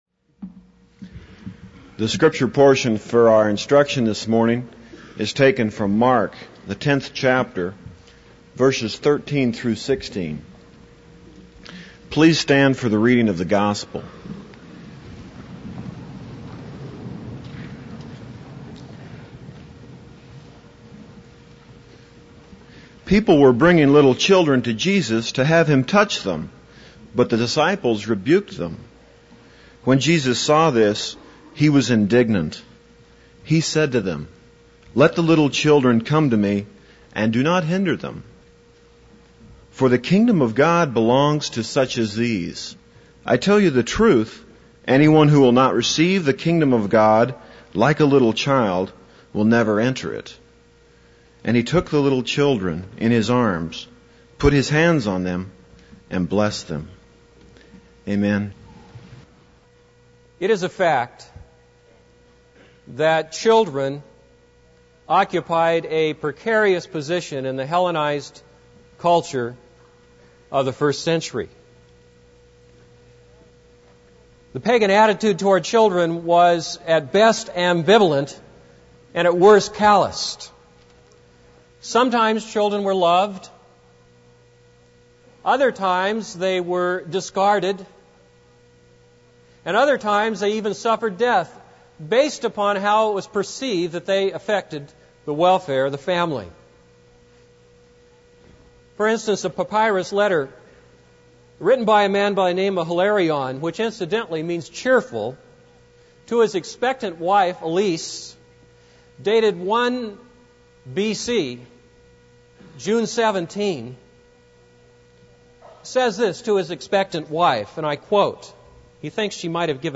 This is a sermon on Mark 10:13-16.